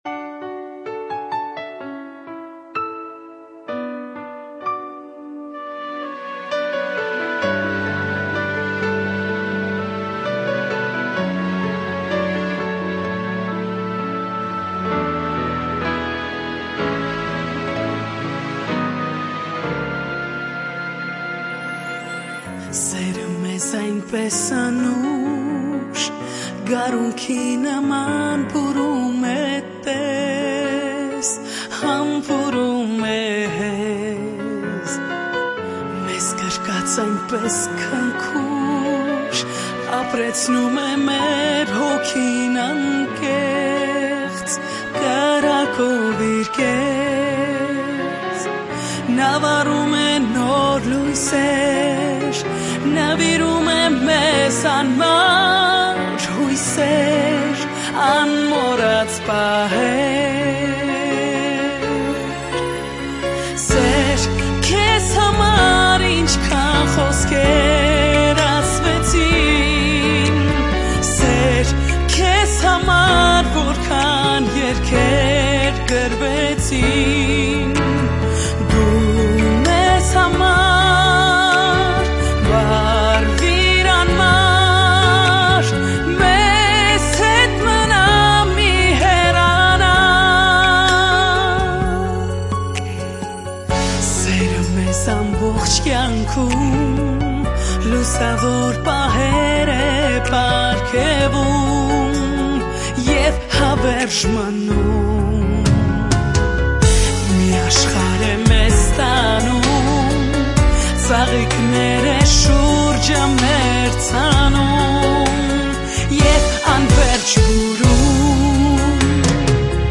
shat gexecik romantik erge shat lavne